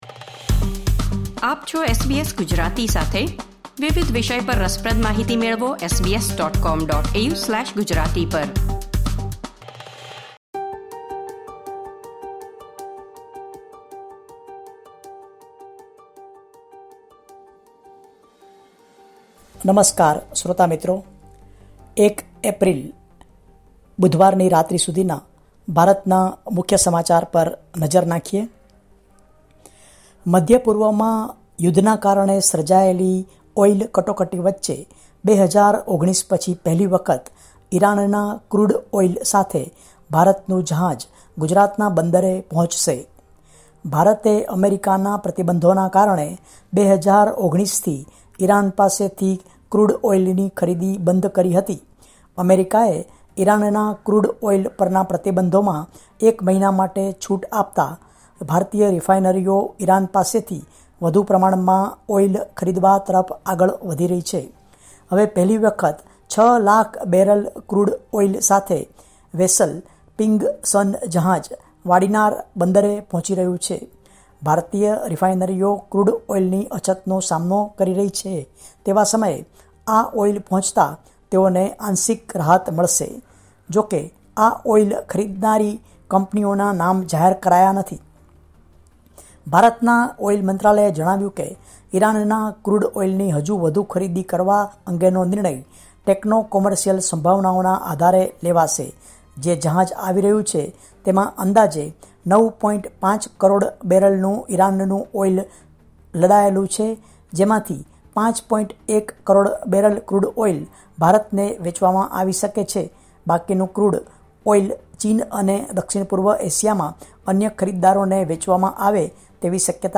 Listen to the latest Indian news from SBS Gujarati.